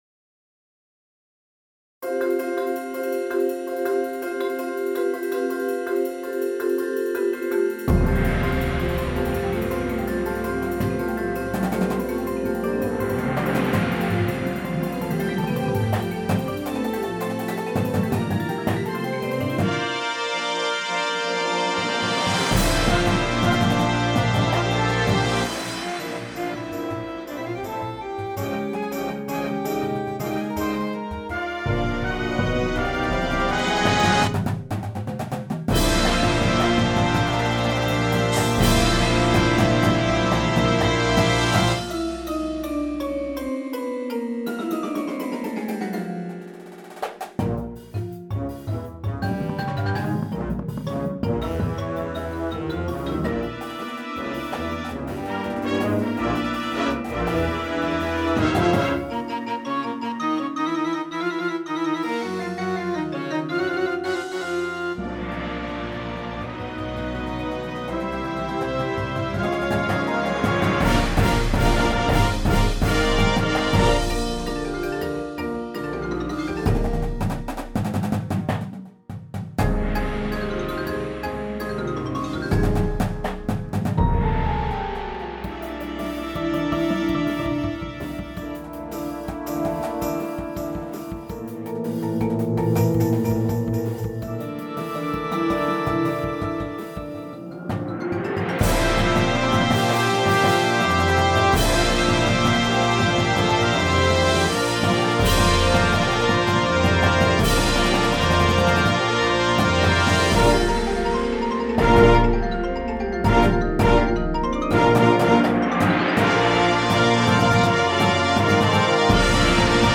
Difficulty: Medium
• Flute
• Trumpet 1
• Tuba
• Snare Drum
• Bass Drums
• Front Ensemble